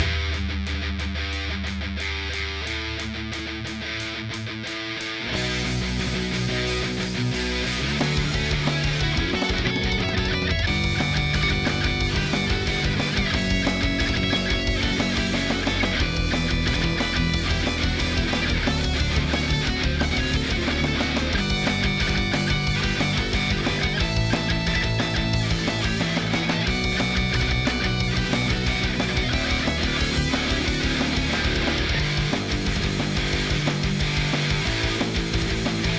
Ongoing mix for Spotify (instrumental high gain guitar) - What would YOU do?
Hi guys, I´m preparing for uploading my first song on Spotify, and I´m in the process of mixing it (i´m super amateur, all free vsts, except the drums). Here I leave a part of the song, based on that, what would you improve? thanks in advance for the constructive criticism